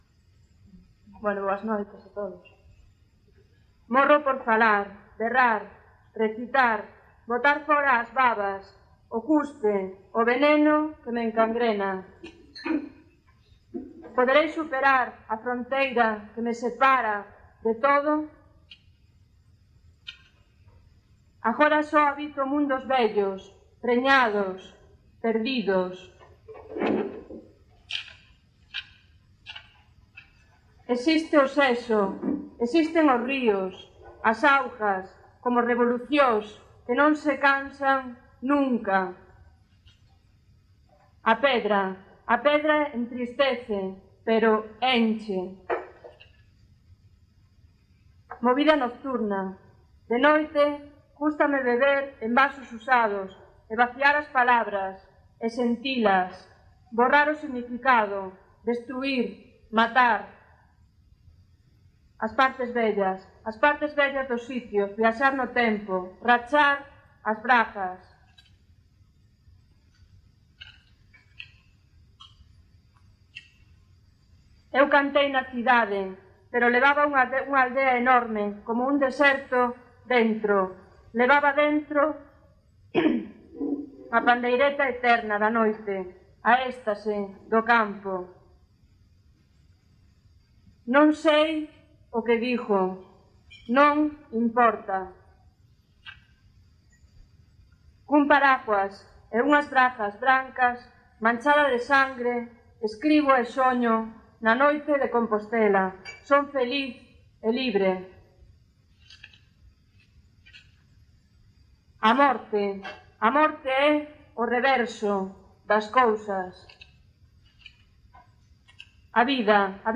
PoesiaGalega: Recital no bar Pepa a Loba
Gravaci�n realizada no bar Pepa a Loba de Santiago de Compostela (r�a do Castro, 7) o 29 de febreiro de 2000. Recital organizado por Letras de Cal e presentado por Marilar Aleixandre.